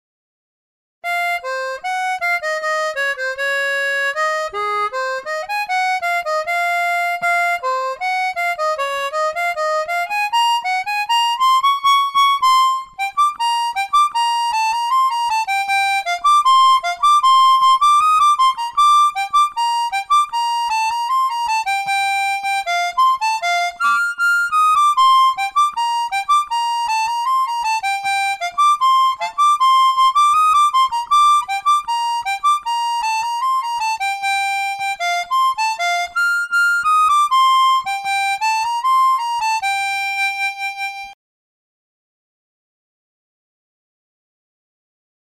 Is it clipping? How to fix it without redoing everything?
I have used the clip repair tool in Sony SF pro demo.
Attachments clipped flute vip.mp3 clipped flute vip.mp3 1.4 MB · Views: 42